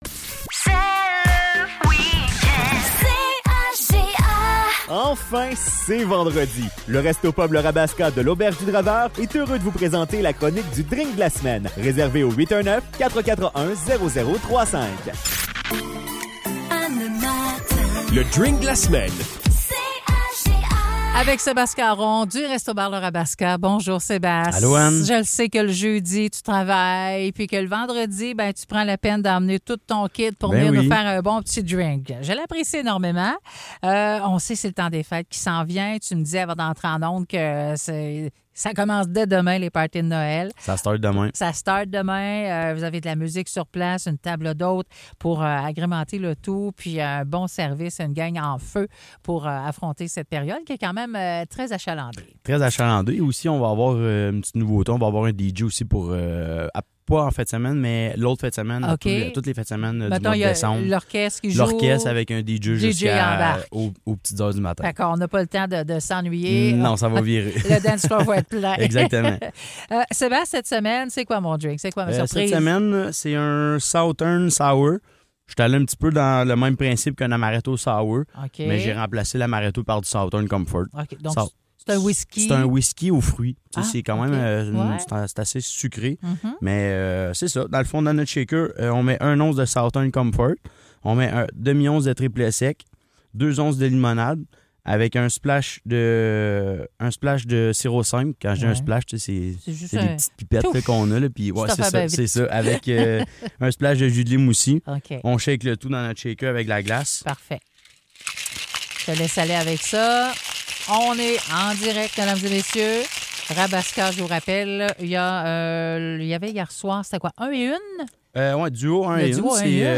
est de passage en studio pour nous présenter le cocktail vedette de la semaine.